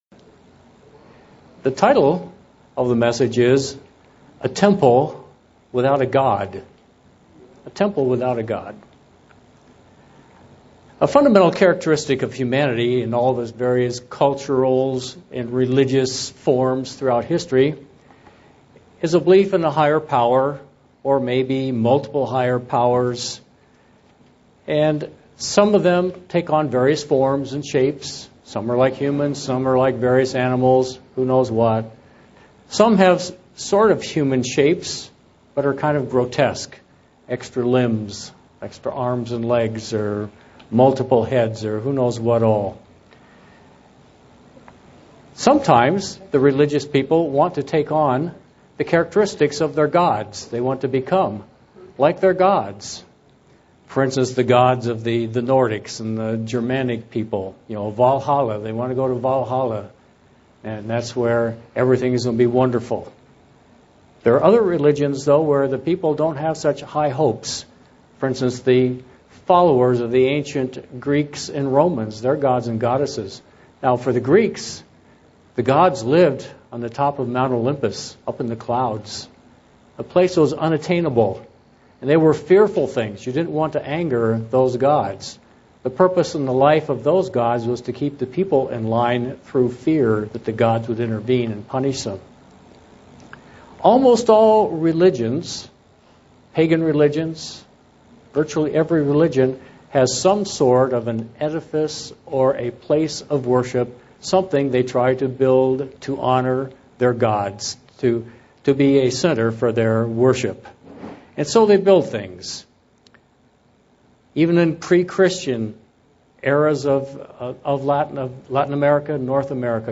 Given in Buffalo, NY
UCG Sermon Studying the bible?